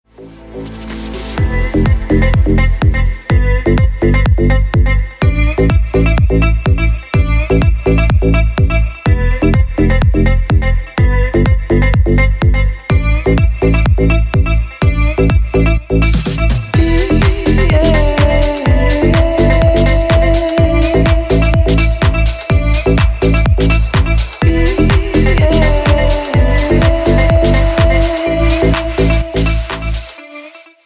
• Качество: 192, Stereo
красивые
deep house
спокойные
без слов